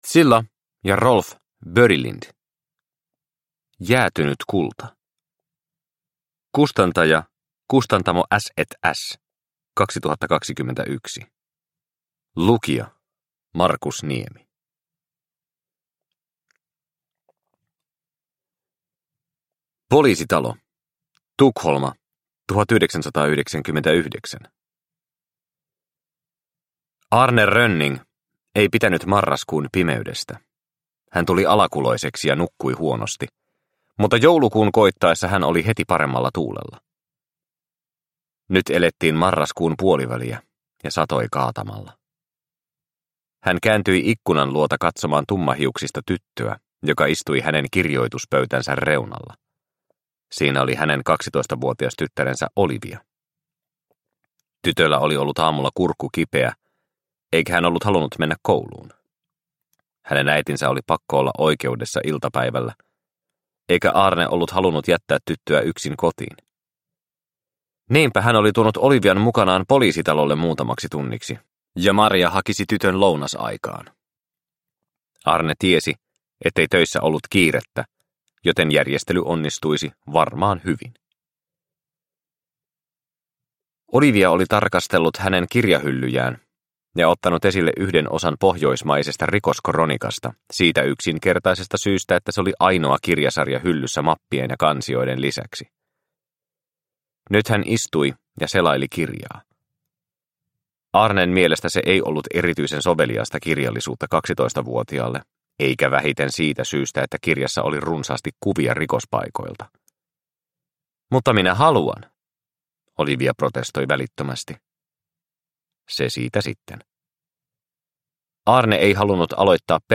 Jäätynyt kulta – Ljudbok – Laddas ner